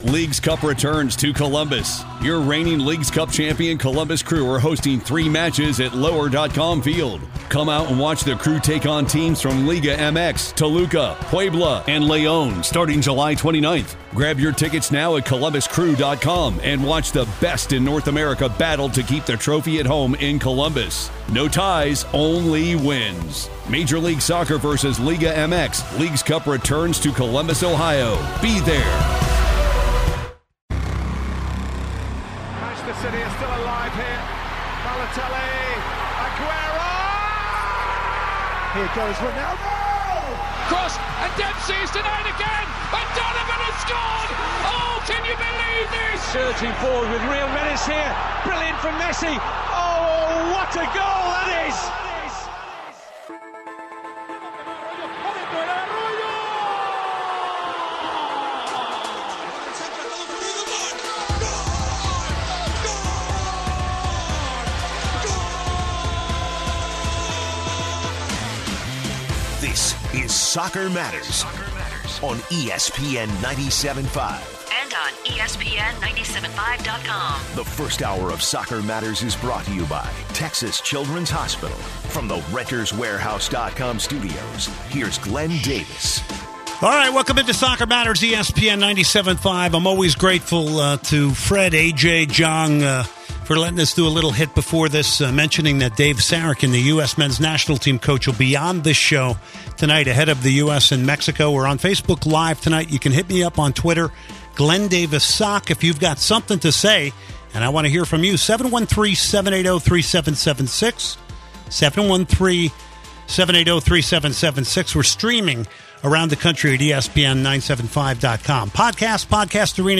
callers’ perspectives on the match
in-depth interview